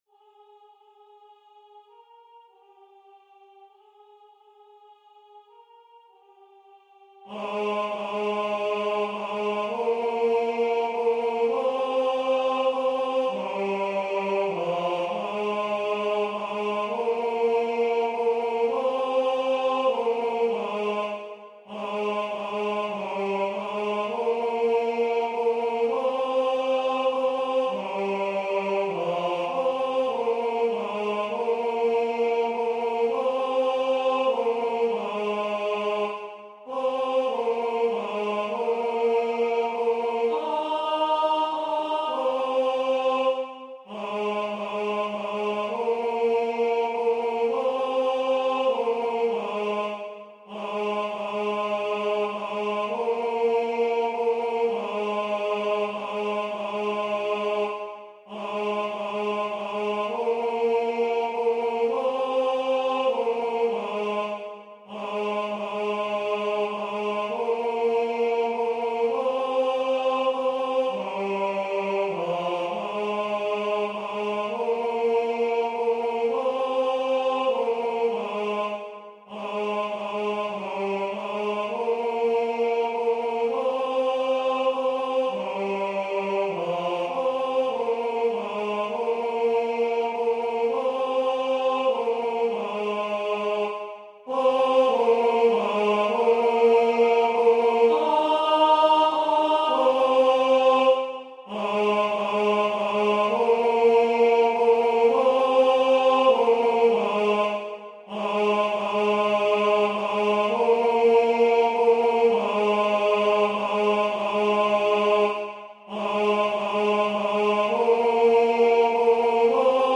- Œuvre pour chœur à 4 voix mixtes (SATB) + 1 voix soliste
Tenor Voix Synth